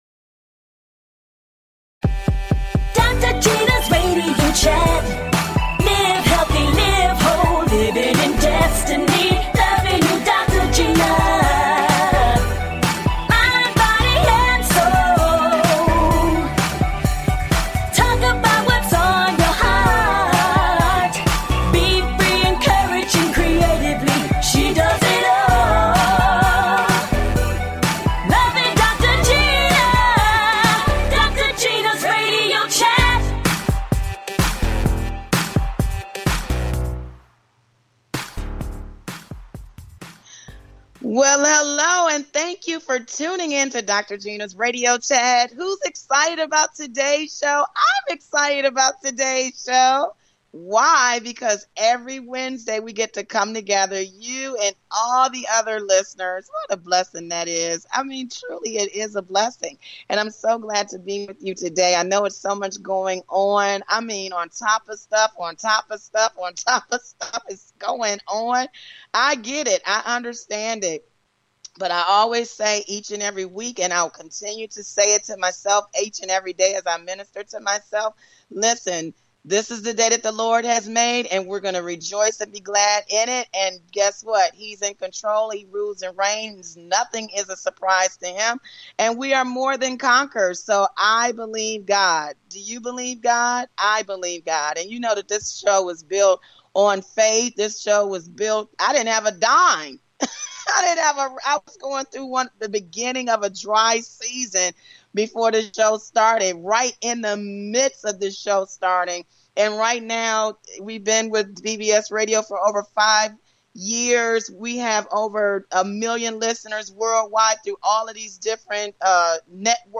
A talk show of encouragement.